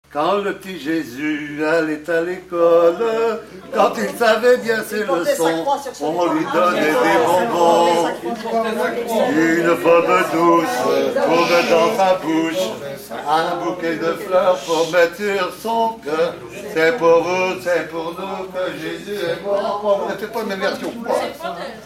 enfantine : lettrée d'école
Enquête Arexcpo en Vendée-C.C. Saint-Fulgent
Pièce musicale inédite